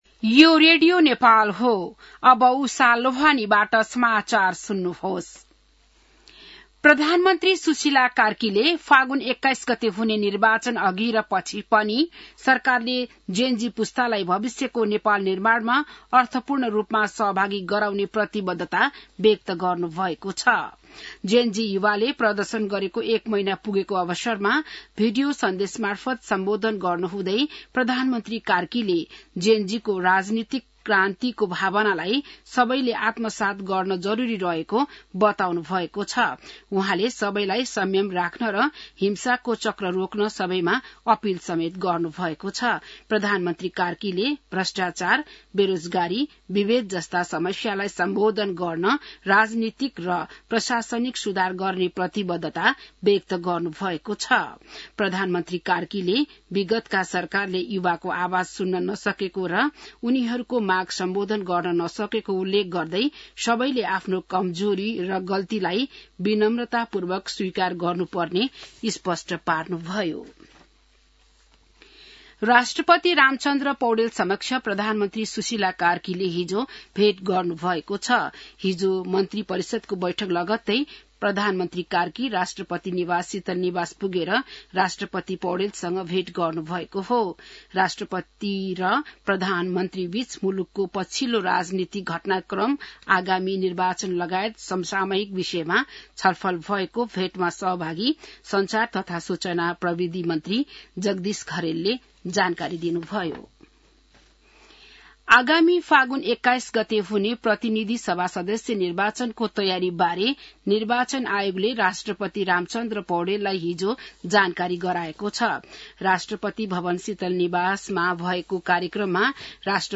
बिहान १० बजेको नेपाली समाचार : २४ असोज , २०८२